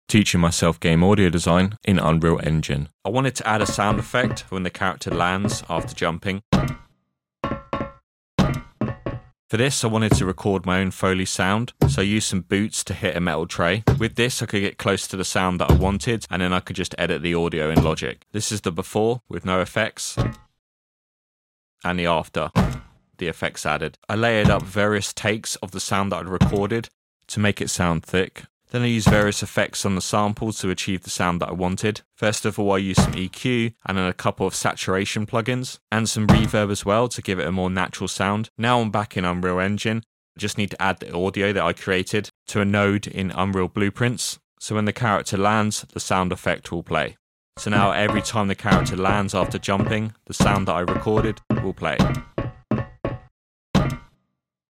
# 5 Teaching myself game audio design in Unreal Engine. Impact sound on landing.